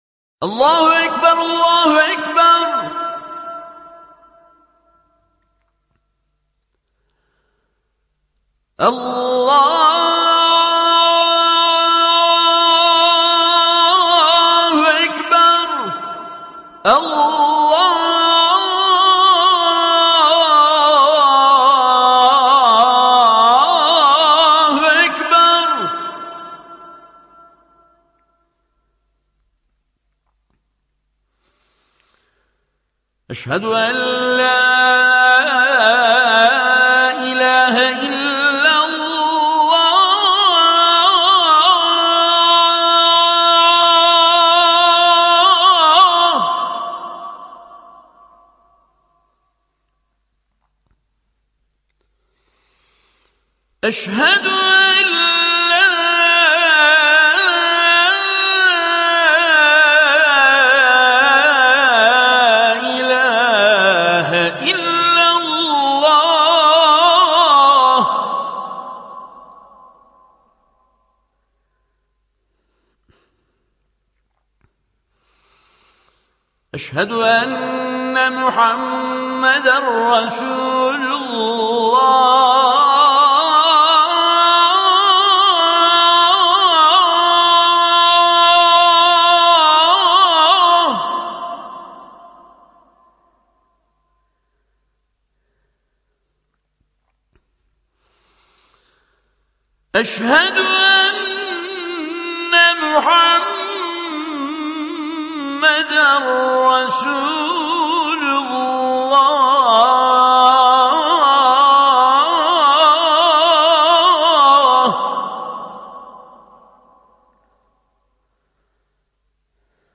athandhuhr.mp3